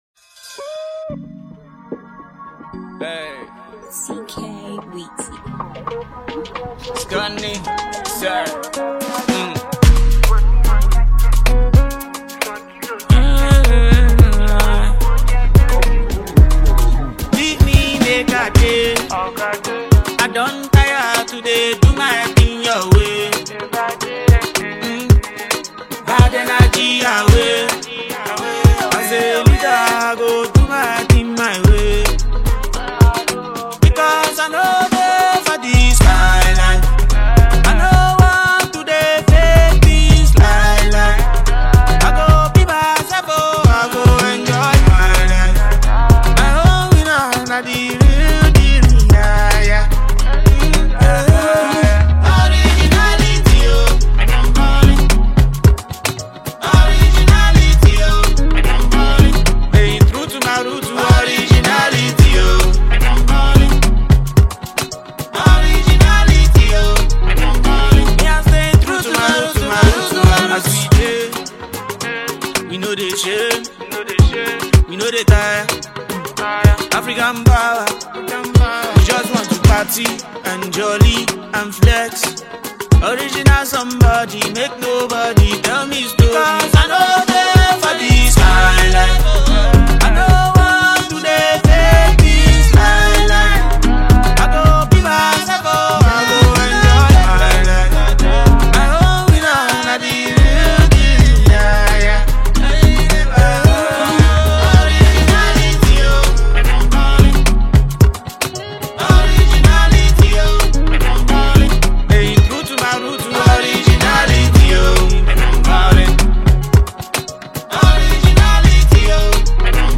rapper, vocalist, and lyricist